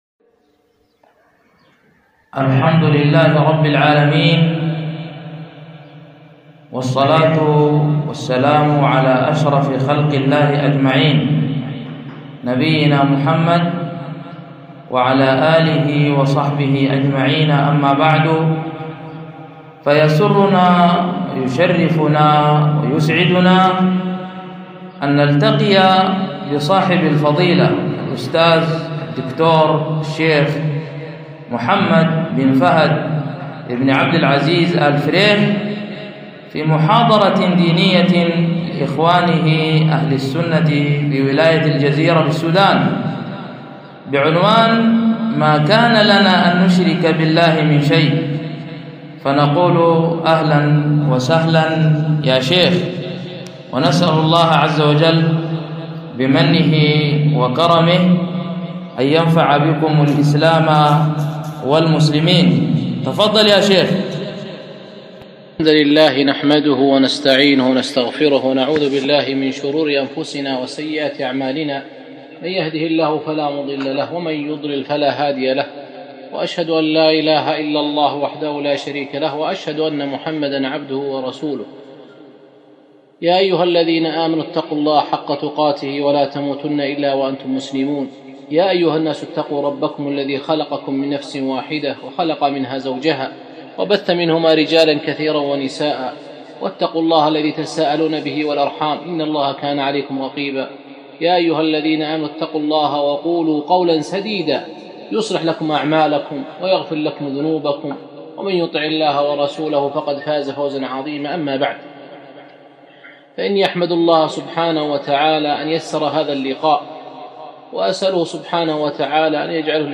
محاضرة - ما كان لنا أن نشرك بالله من شيء